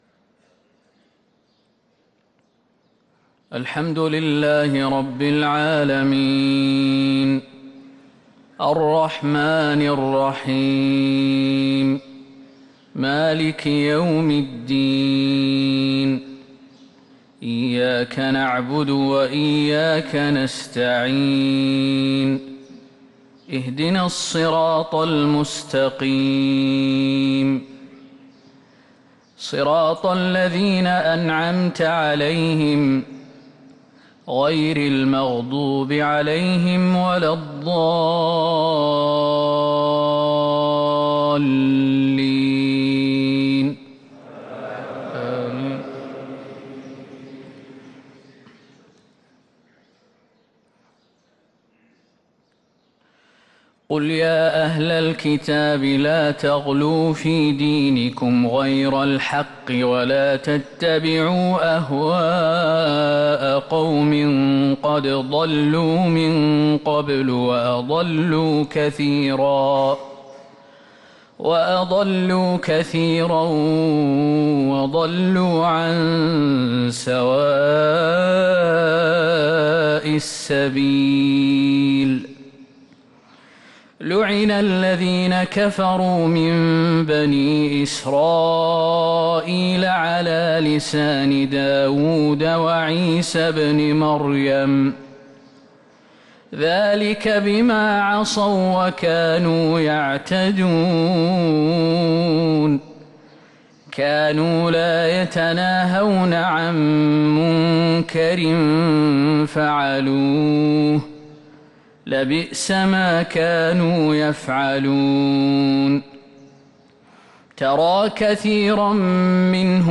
صلاة الفجر للقارئ خالد المهنا 20 جمادي الآخر 1444 هـ
تِلَاوَات الْحَرَمَيْن .